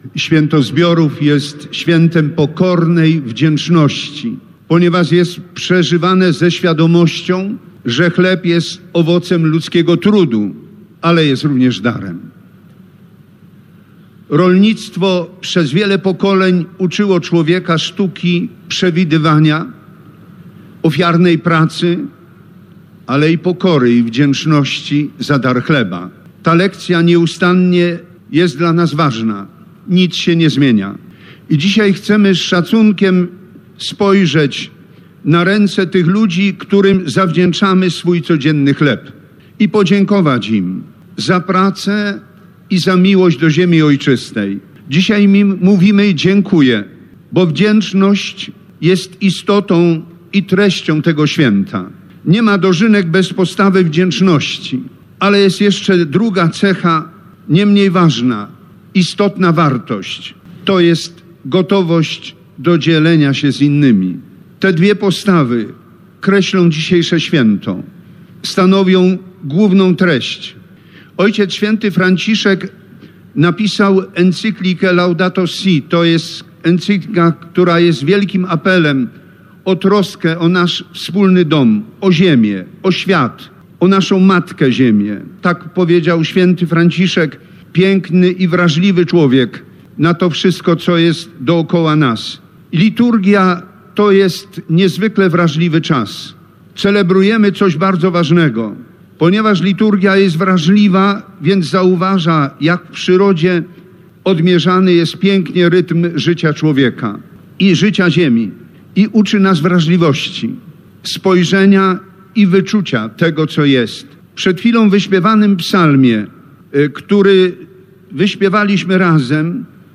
Bp Edward Dajczak przewodniczył Mszy św. podczas ogólnopolskich dożynek w Bobolicach.
Uroczystości w Bobolicach 29 sierpnia odbyły się pod hasłem "Wdzięczni Polskiej Wsi".
Dożynki_bpED_kazanie.mp3